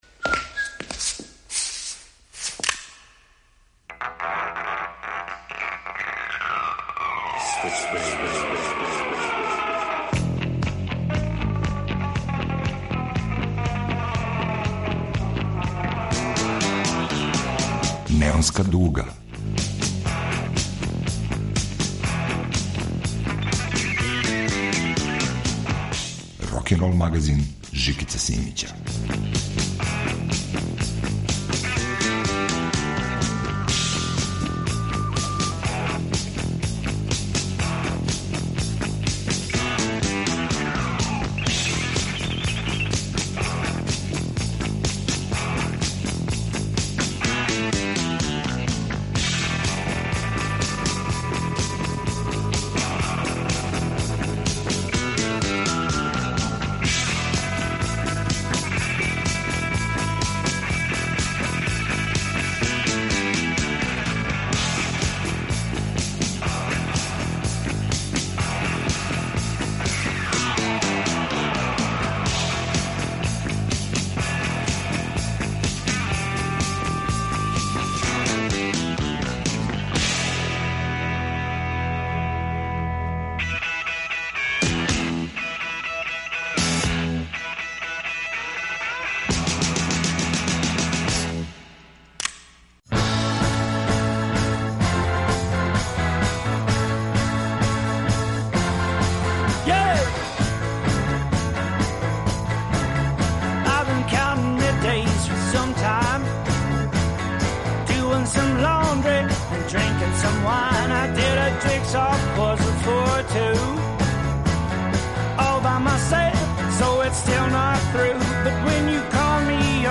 Рокенрол као музички скор за живот на дивљој страни.
Неонска дуга се враћа текућој продукцији. 24 песме су на репертоару. Звук који повезује модерно и класично.